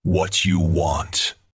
Subject description: Yuri Unit's voice pack with a style that leans towards bland, cold, and serious   Reply with quote  Mark this post and the followings unread